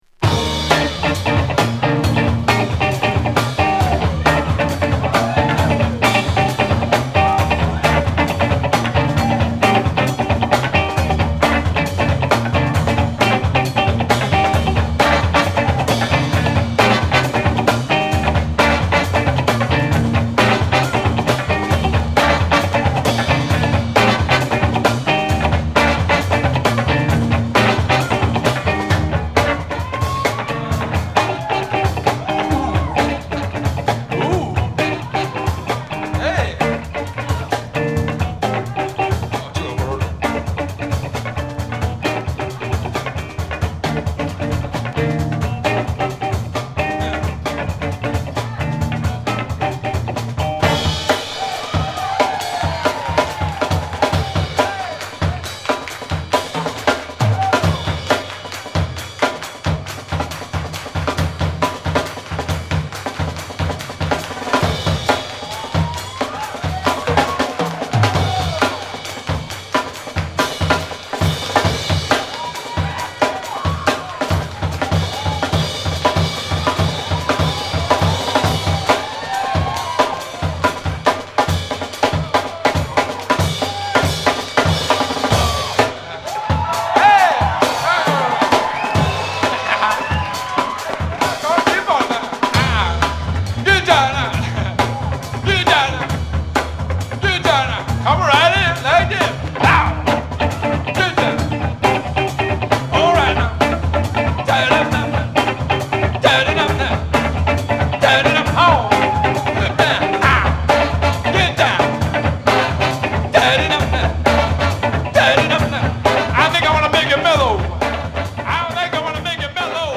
ファンク・カヴァー